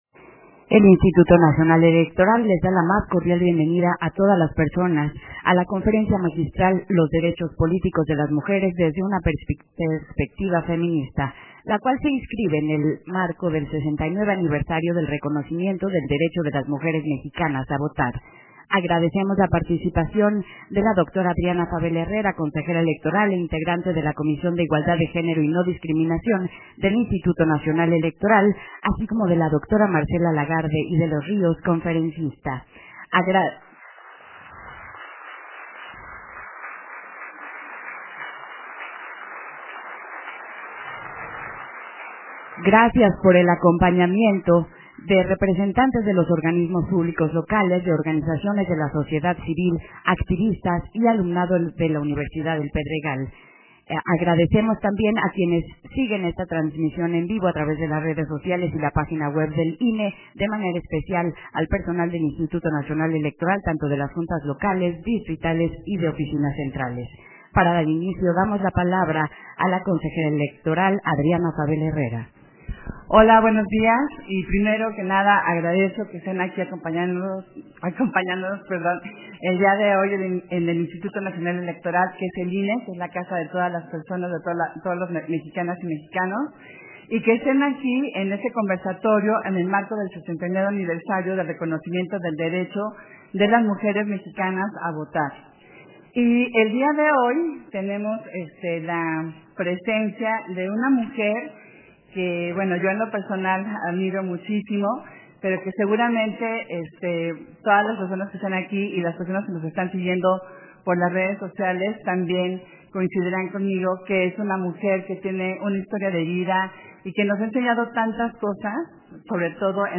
Versión estenográfica de la Conferencia Magistral: Los derechos políticos de las mujeres desde una perspectiva feminista